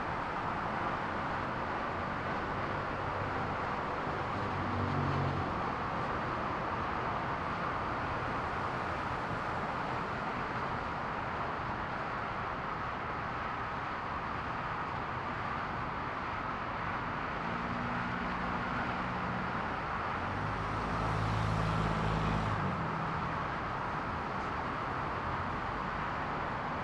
Late Morning City Traffic ST450_ambiX.wav